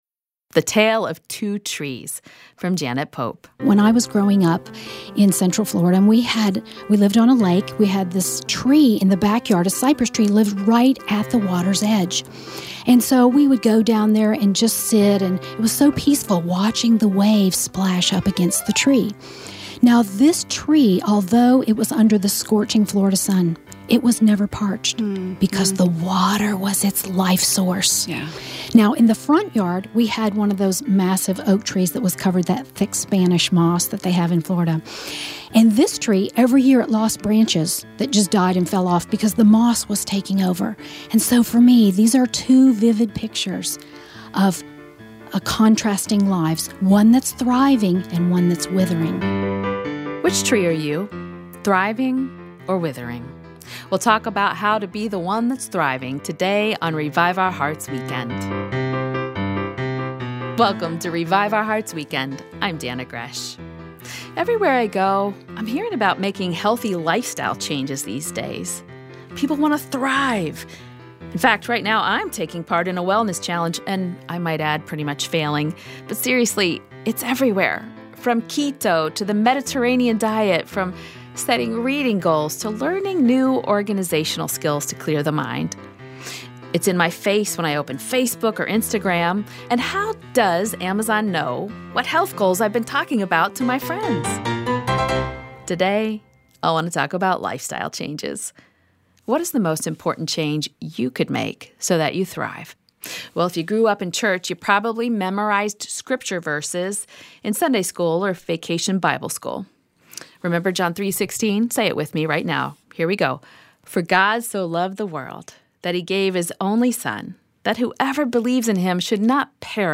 A few busy women will share how they memorize Scripture when doing their hair and makeup or waiting in line at McDonalds.